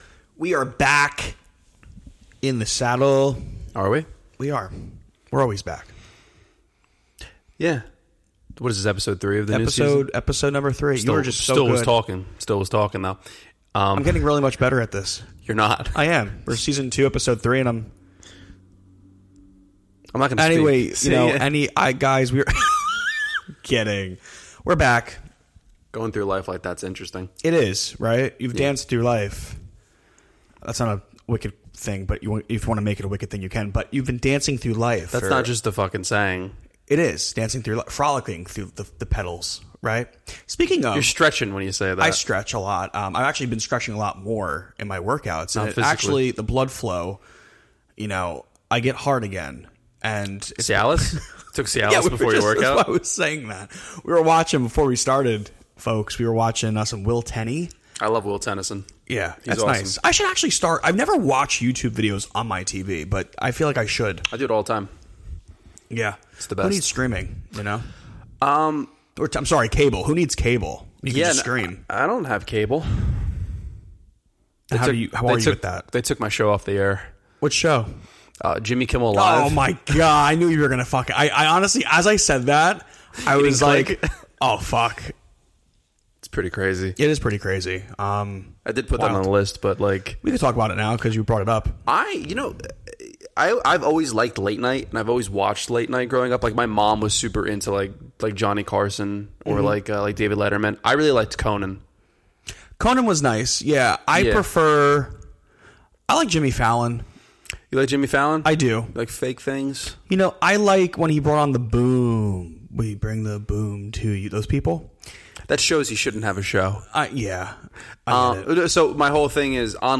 Be a guest on this podcast Language: en Genres: Comedy , Improv , TV & Film Contact email: Get it Feed URL: Get it iTunes ID: Get it Get all podcast data Listen Now...